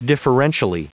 Prononciation du mot differentially en anglais (fichier audio)
Prononciation du mot : differentially